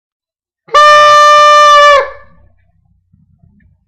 mini-performance di un minuto
è di essere fugace, effimero, laconico,